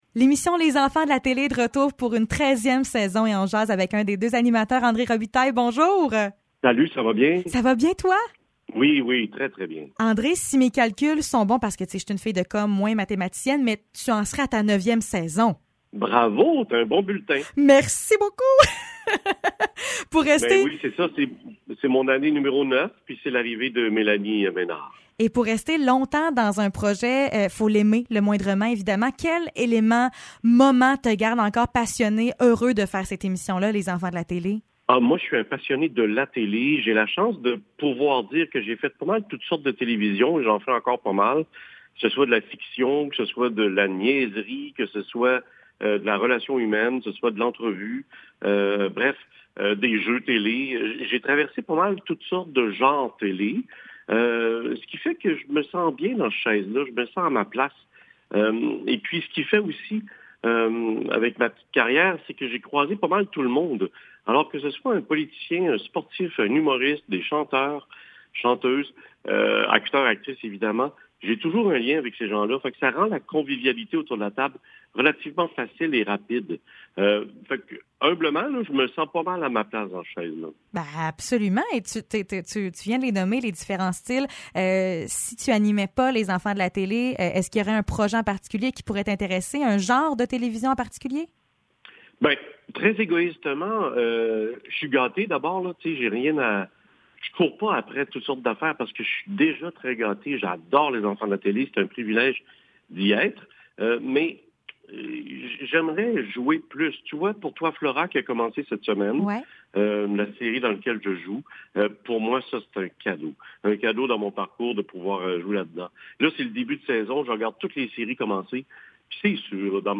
Entrevue avec André Robitaille